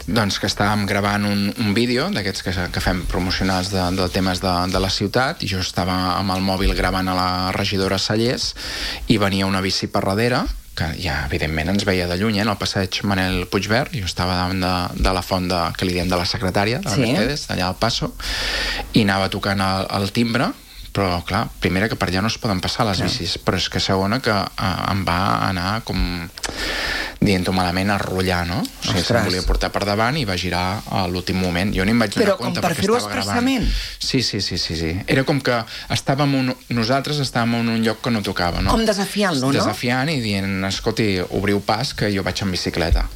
Xavier Ponsdomènech, regidor i portaveu d’ERC, que aquest dilluns ha passat per l’espai de l’entrevista, ha defensat la creació d’un pla d’usos per regular els tipus d’establiments al centre.